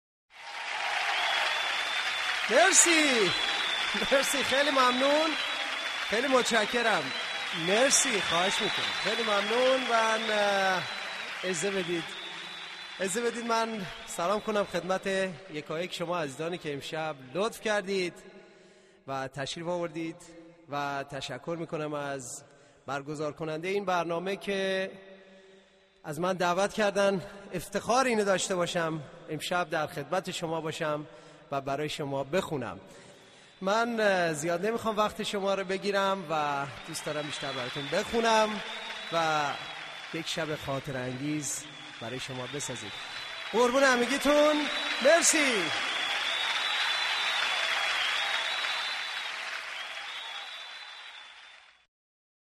صدای محزون و دلنشینی داشت.